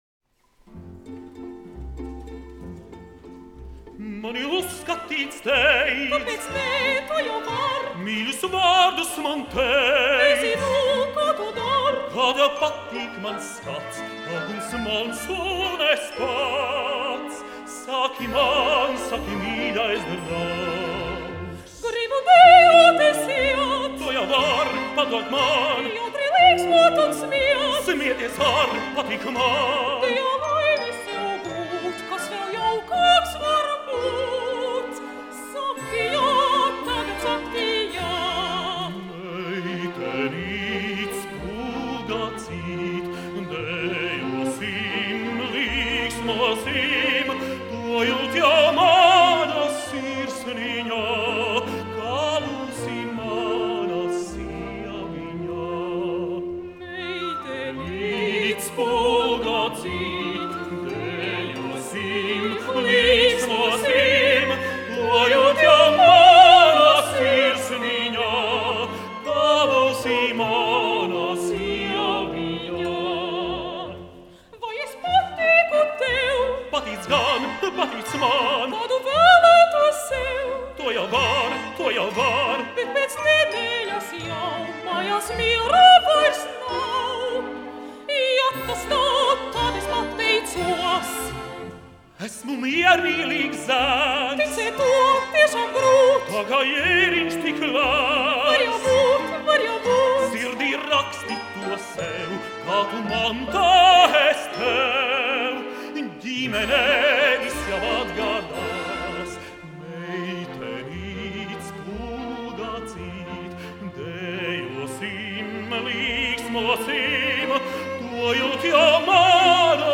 Maija Kovaļevska, solists
LNSO orķestris, izpildītājs
Dueti
Aplausi !
Mūzika no operetes
Siguldas estrāde